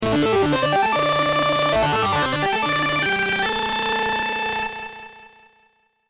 • e牙狼12黄金騎士極限／GOD OF GARO 7500獲得音